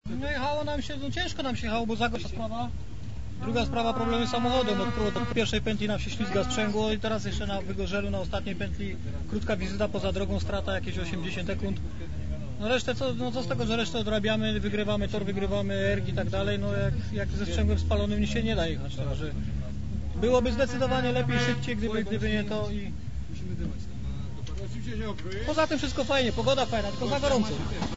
wypowiedź - 8 Rajd AC Pokale-Mera Tychy - meta rajdu